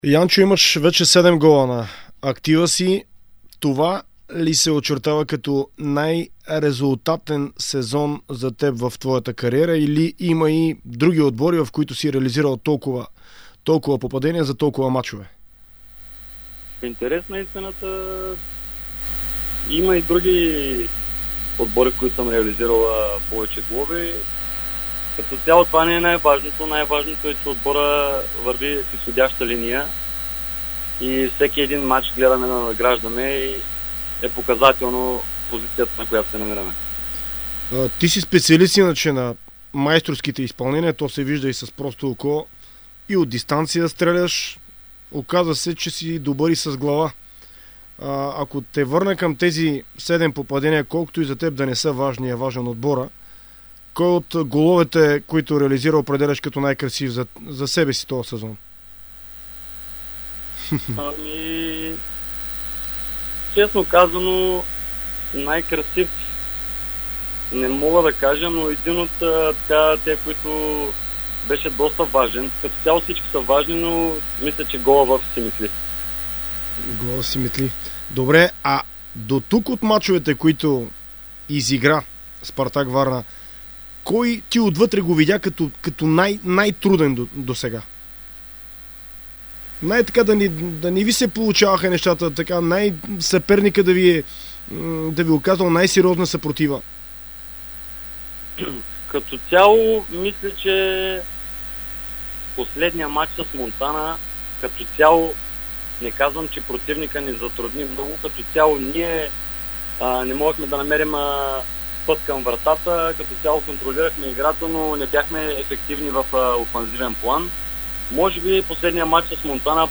В интервю за Дарик радио и dsport халфът говори за шансовете на настоящият му тим да влезе в елита, за основните конкуренти, за най-трудния мач до момента, както и за това дали има предложения от други клубове.